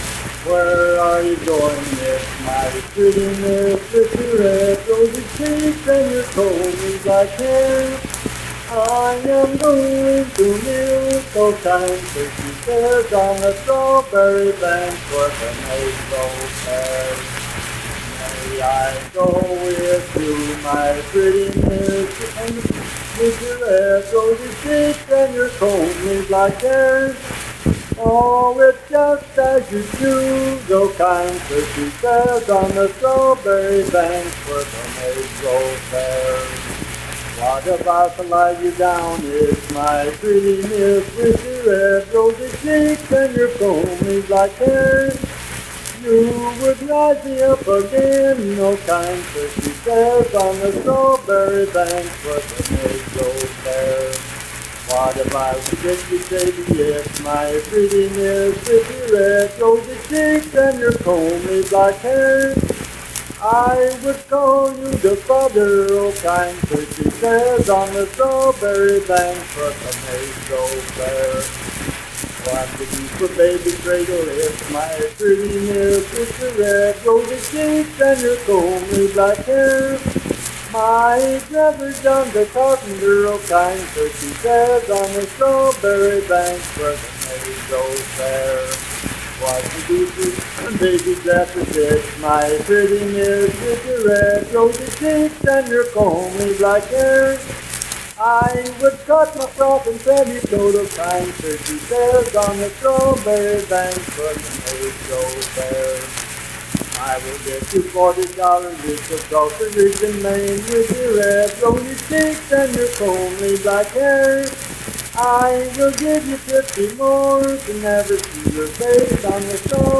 Unaccompanied vocal music performance
Dance, Game, and Party Songs, Love and Lovers
Voice (sung)
Randolph County (W. Va.)